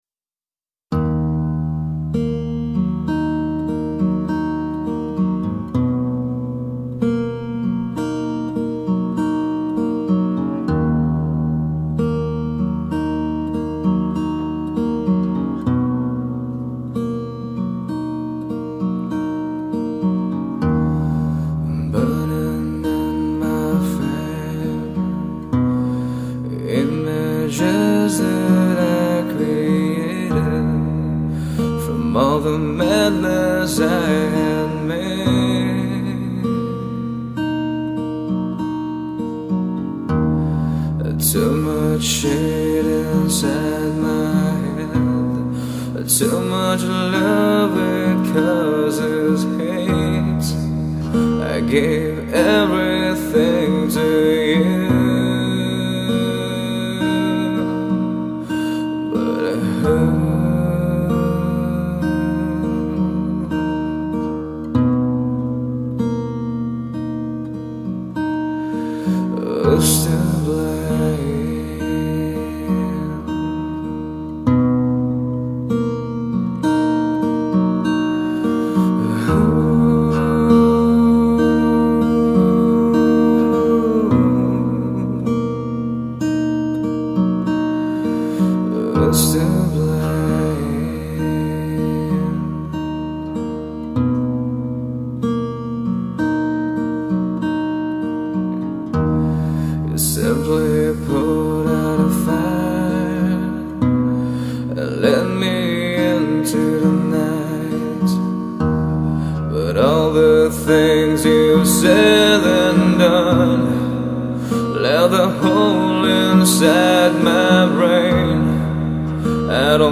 Singer | Songwriter
soul-stirring vocals accompanied by an acoustic guitar